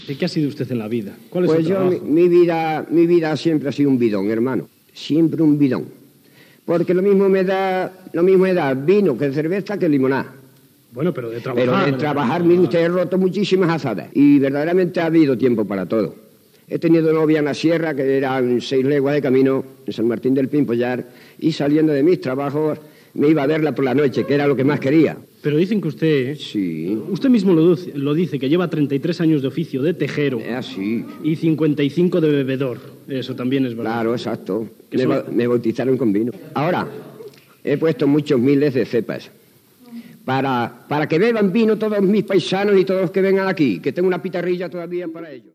Sèrie “Aldeas Rurales”, amb una entrevista a un teixidor de poble i camperol
Info-entreteniment